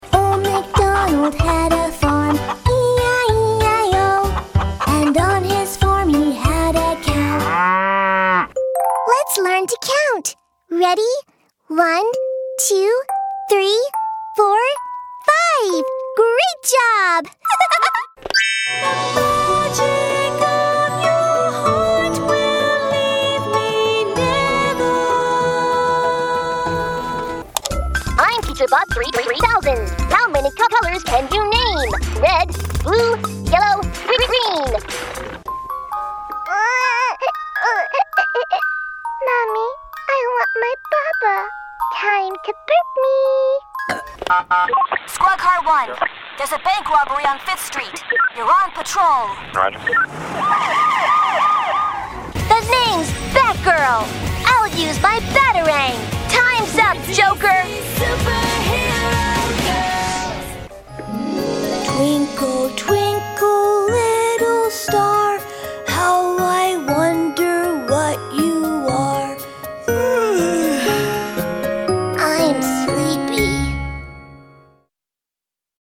Young Adult, Adult
Has Own Studio
standard us | natural